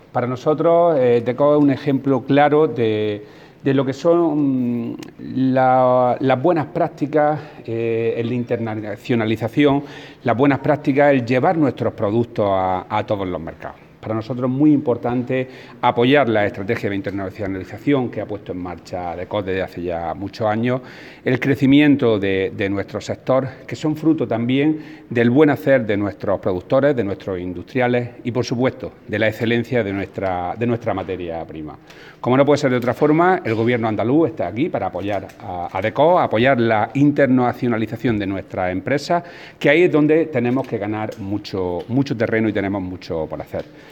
Declaraciones del consejero sobre DCOOP y la internacionalización del sector agroalimentario